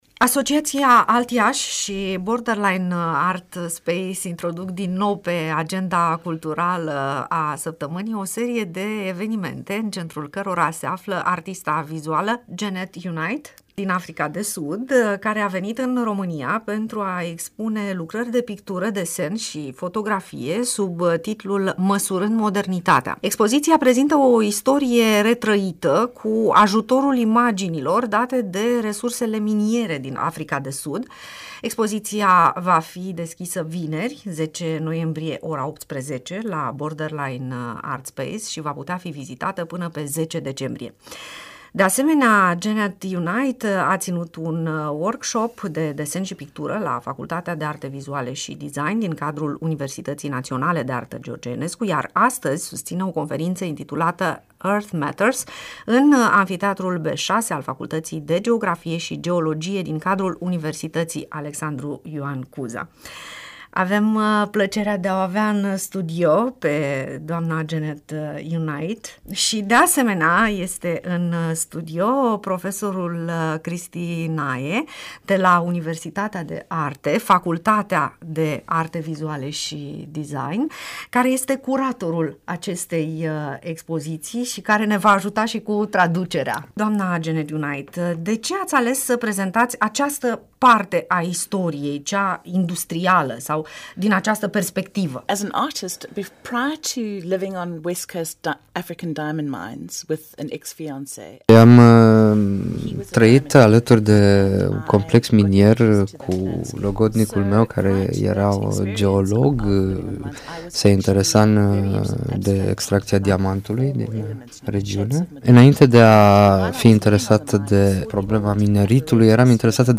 O istorie a Africii de Sud scoasă la lumină cu mijloacele artei vizuale - INTERVIU
Interviu-Africa-de-Sud-bun.mp3